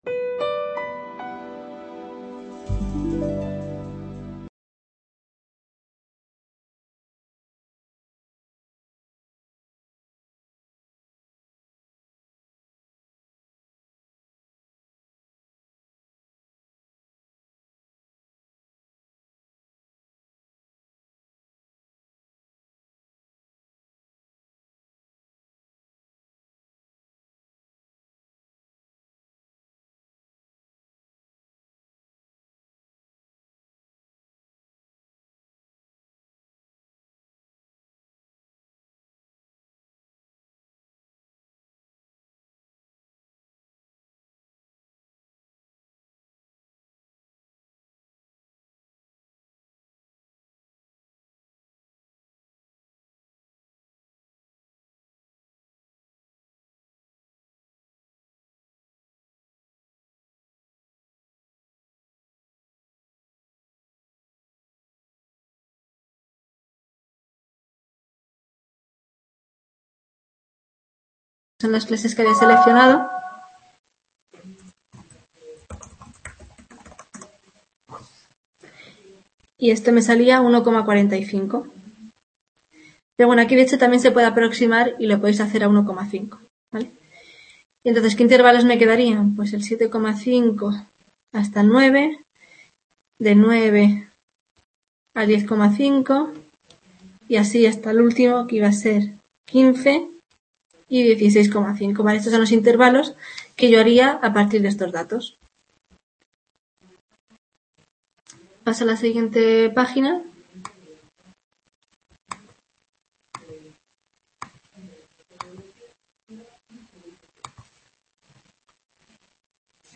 Tutoria Estadística Básica- Estadistica Descriptiva II | Repositorio Digital